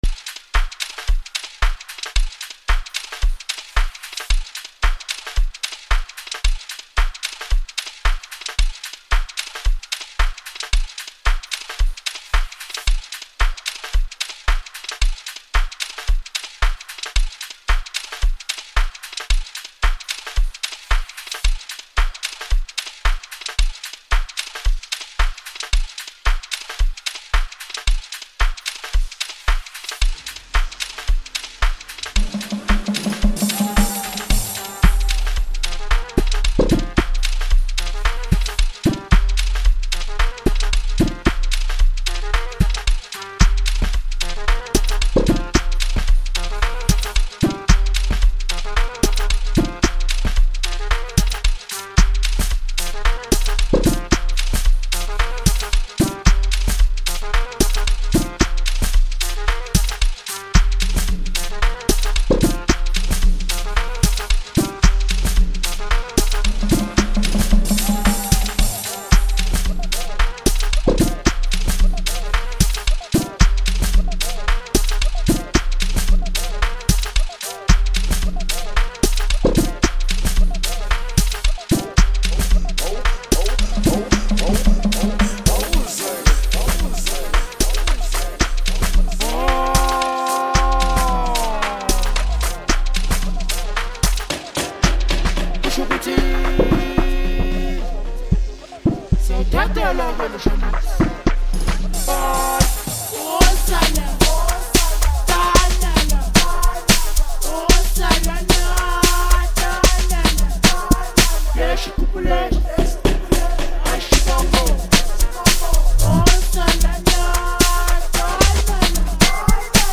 ” offering smooth vocals
rich production